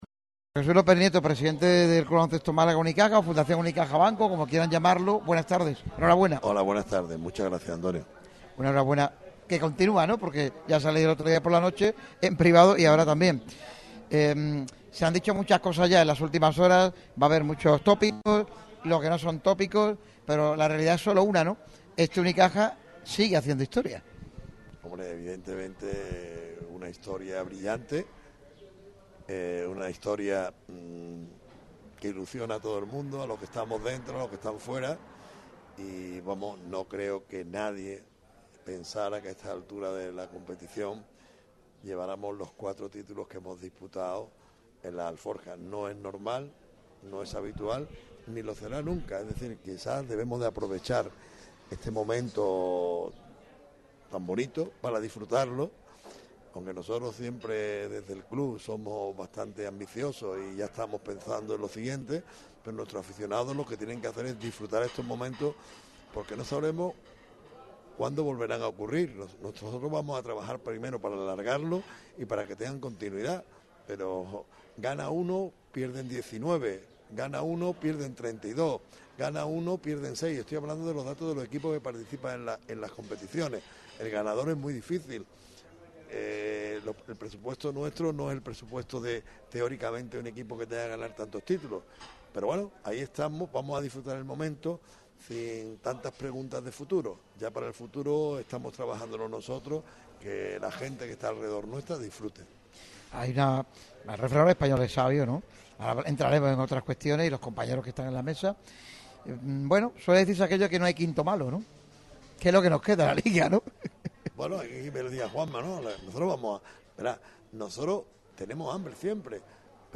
La Pondera Playa ha acogido la cita en la que los dos dirigente del equipo de moda han repasado toda la actualidad cajista.